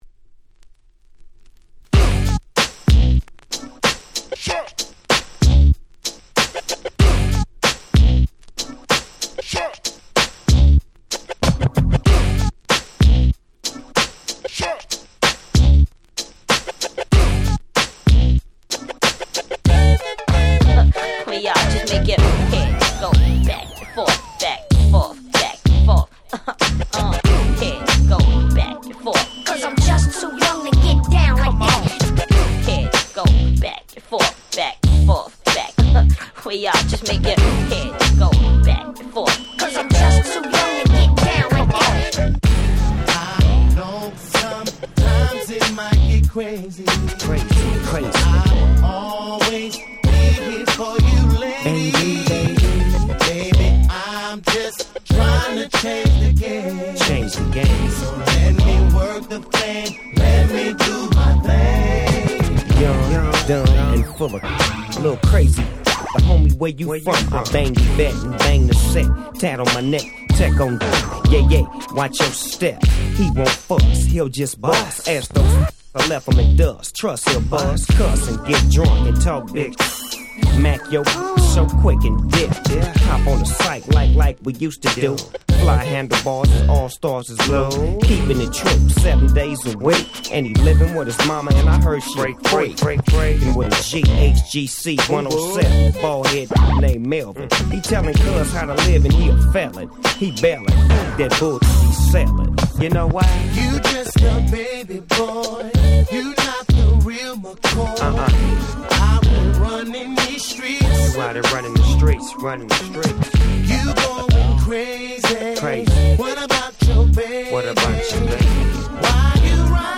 自分が当時好んで使用していた曲を試聴ファイルとして録音しておきました。
90's R&B